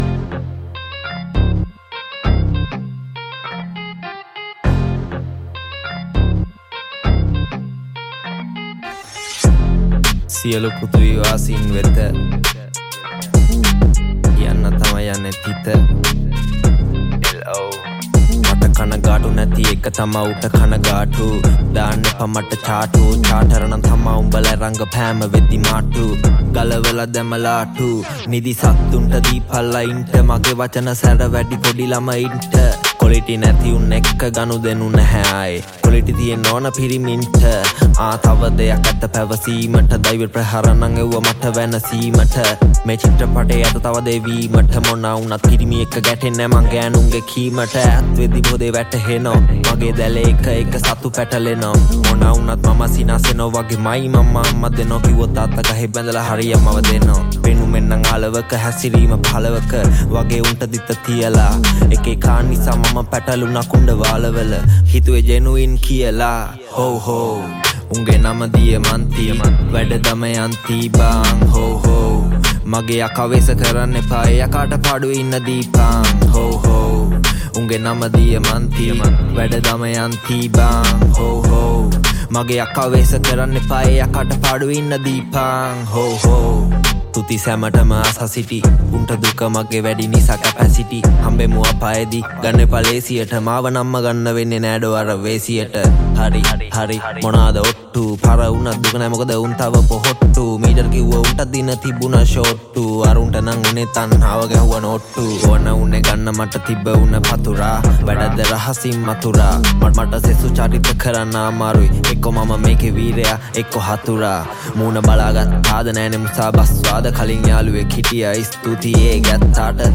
Sl Rap